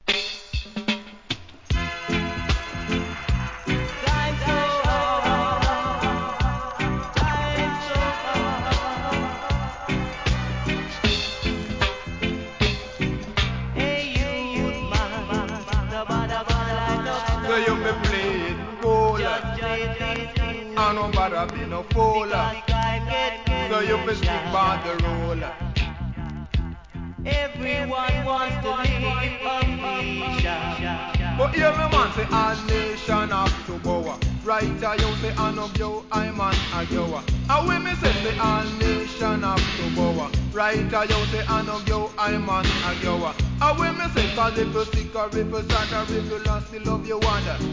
REGGAE
DeeJay CUT!!